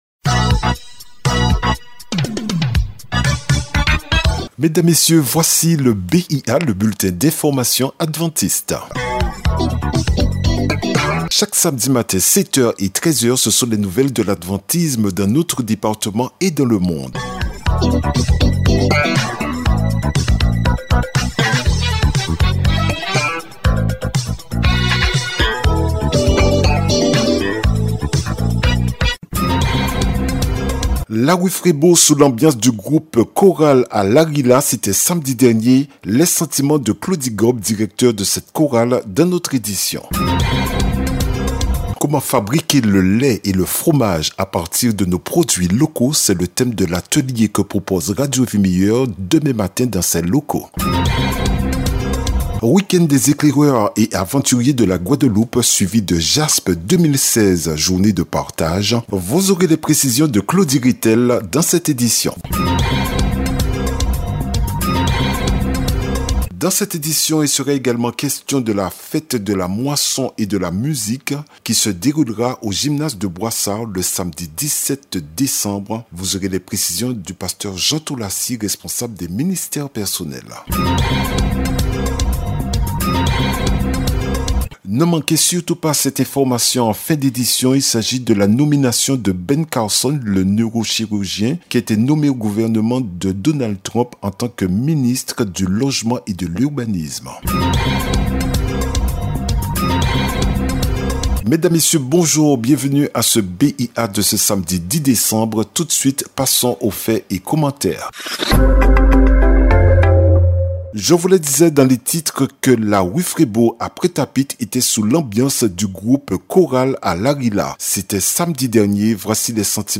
Le journal peut être écouté directement ou il peut être téléchargé.